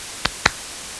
clap-01_noise0.02.wav